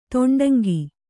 ♪ to'ṇḍaŋgi